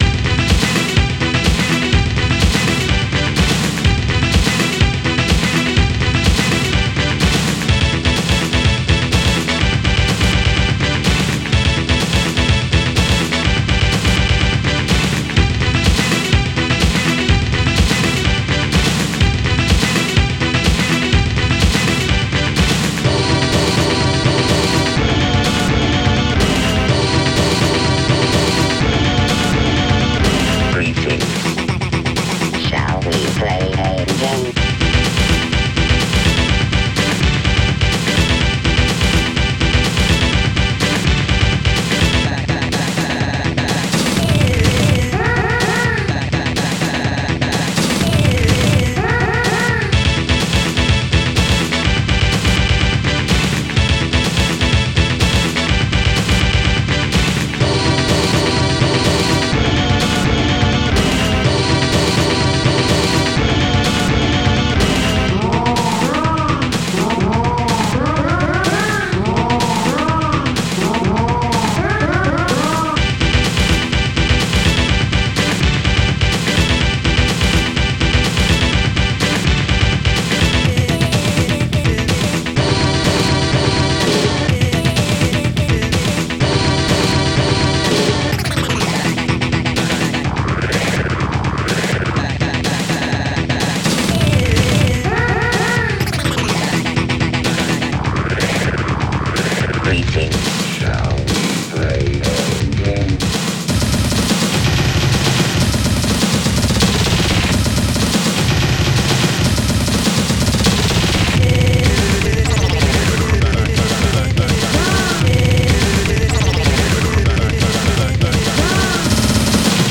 Star Tracker/StarTrekker Module
2 channels